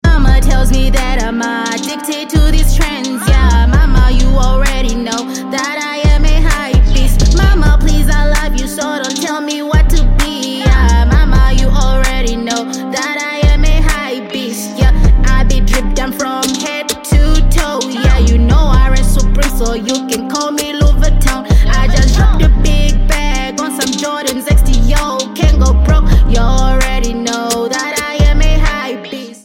Legendary Pitchcorrection 💎 No Latency! sound effects free download